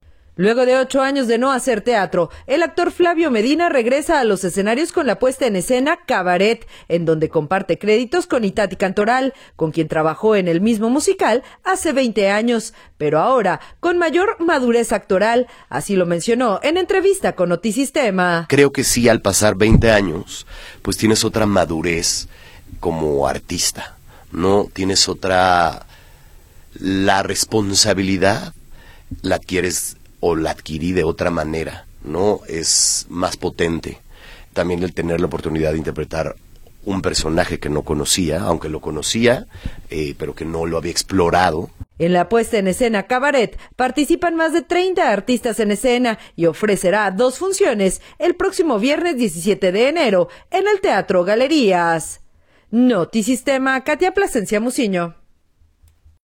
Luego de ocho años de no hacer teatro, el actor Flavio Medina regresa a los escenarios con la puesta en escena “Cabaret” en donde comparte créditos con Itatí Cantoral, con quien trabajó en el mismo musical hace 20 años, pero ahora con mayor madurez actoral, así lo mencionó en entrevista con Notisistema.